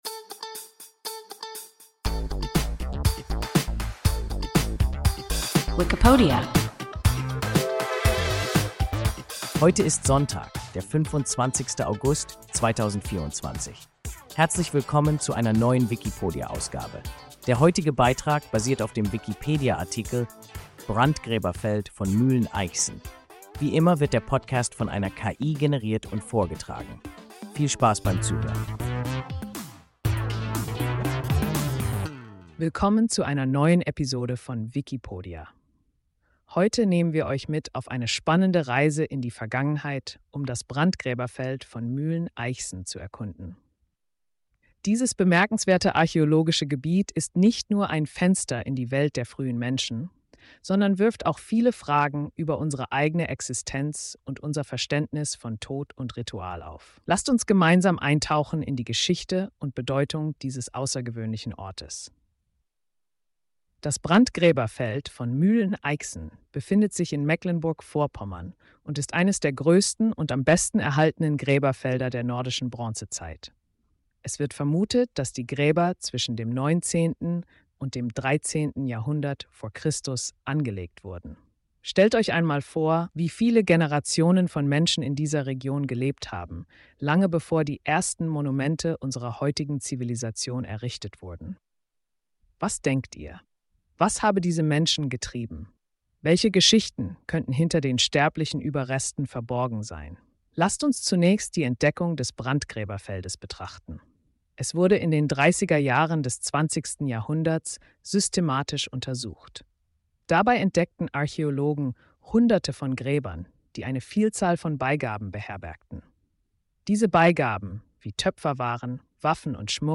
Brandgräberfeld von Mühlen Eichsen – WIKIPODIA – ein KI Podcast